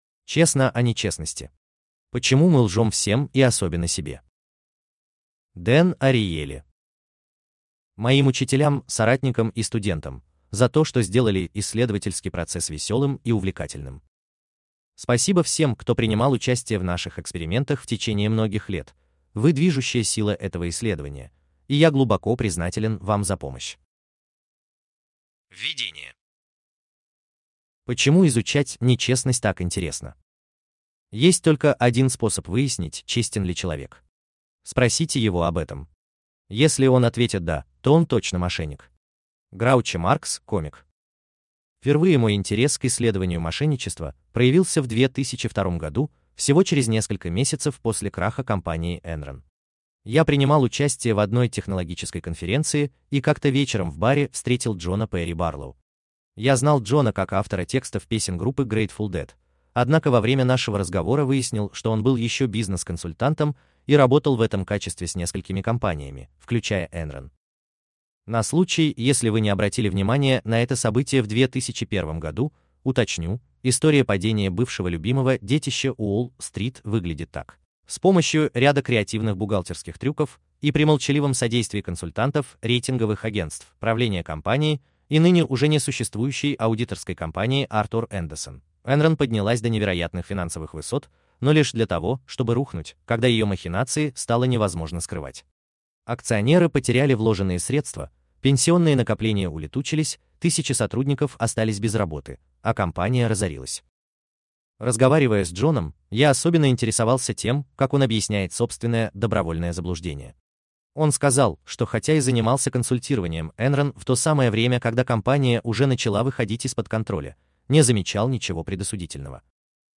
Аудиокнига Честно о нечестности | Библиотека аудиокниг